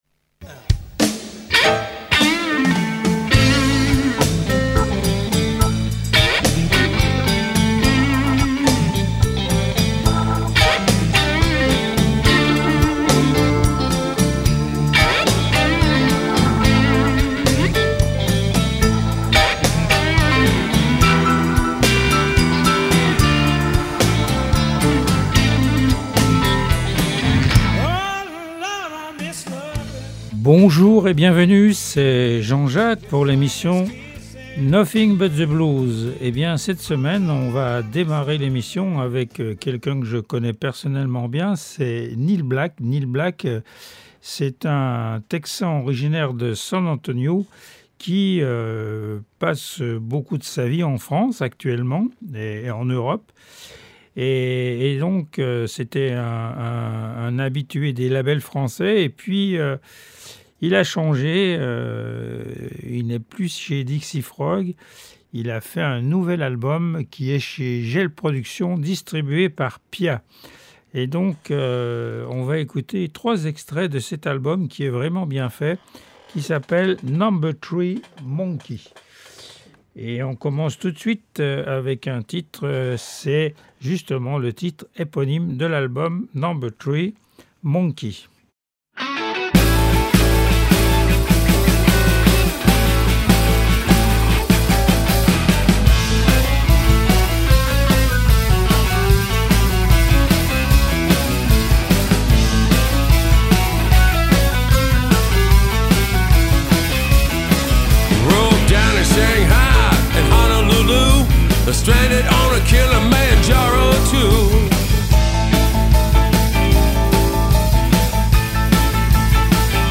Du traditionnel au blues rock actuel.
L’émission offre un espace aux musiciens Lorrains et à la particularité de présenter de la musique en Live et des interviews.